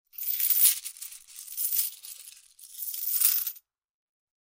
Money-sound-effect.mp3